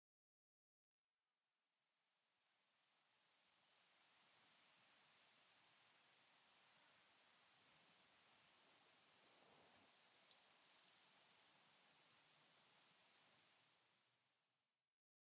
crickets.ogg